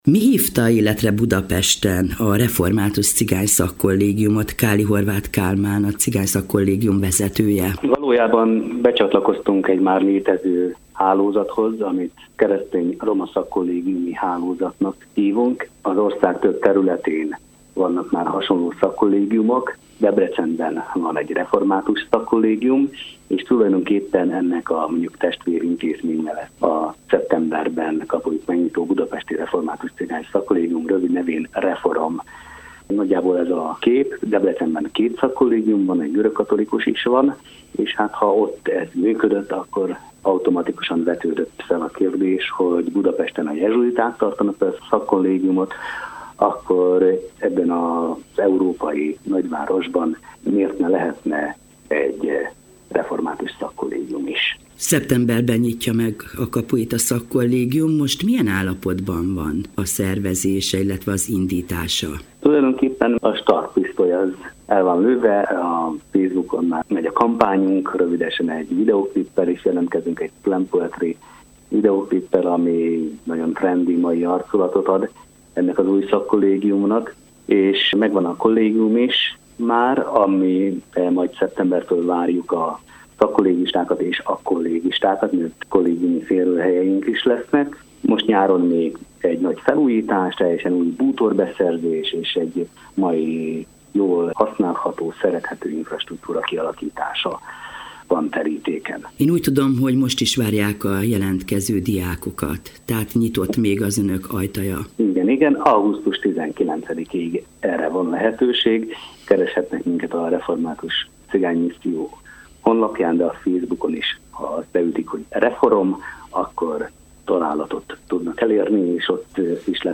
A részletekről szól a következő beszélgetés: